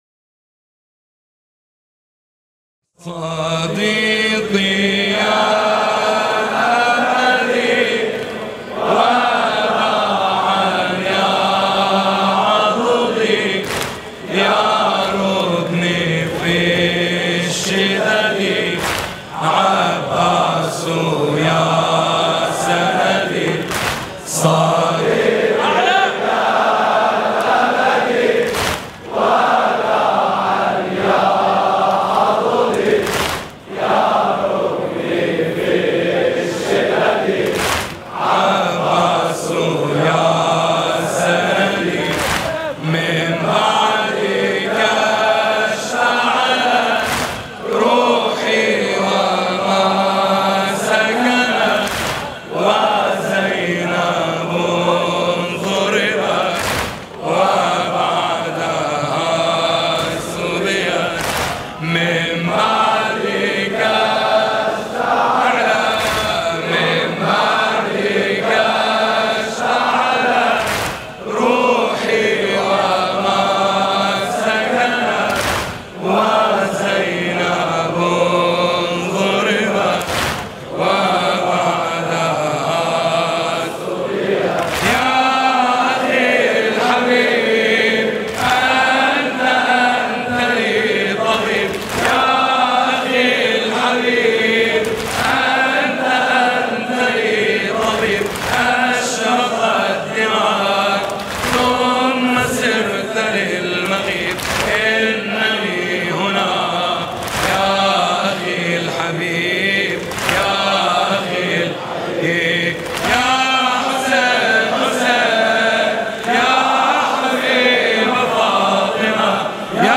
سنگین (عربی) | يا رُكني في الشَّدَدِ ...عباسُ يا سَندِي
مداحی